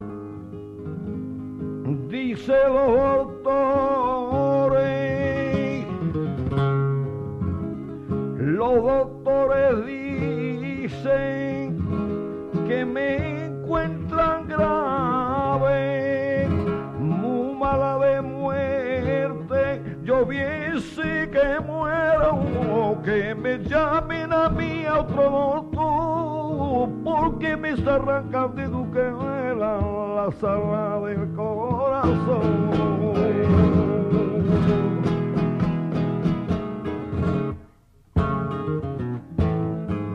Tientos (otros)